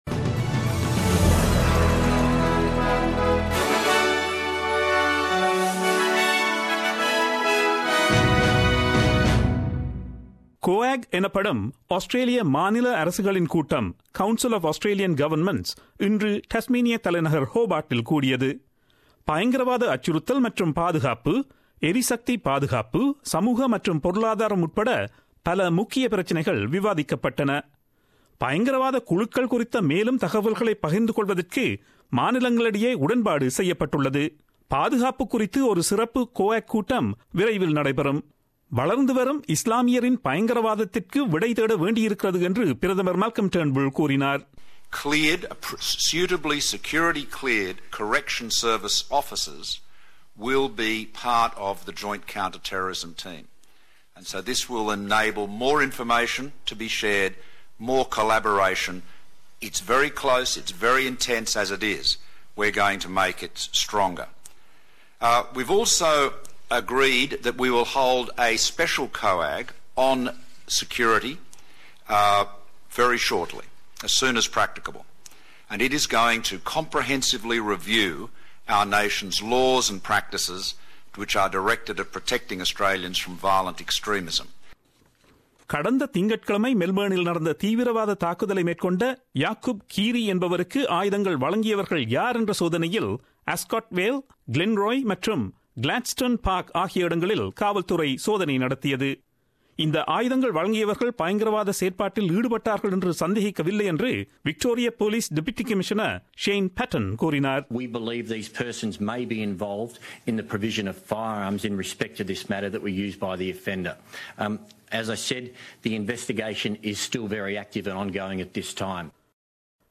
Australian news bulletin aired on Friday 09 June 2017 at 8pm.